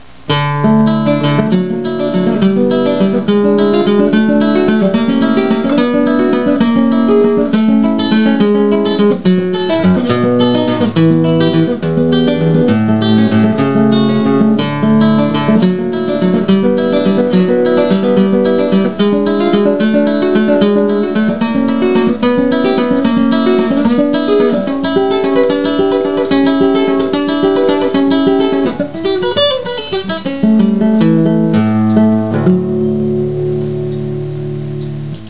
Tuning: DADGBE Key: G major/D major Sample:
Comments: This is a waltz composition of a medium difficulty level.